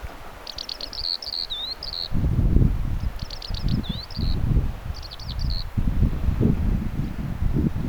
tuollaisia kiurun ääniä
Kiuruja oli rantaniityllä aivan hämmästyttävä määrä.
tuollaisia_kiurun_aania.mp3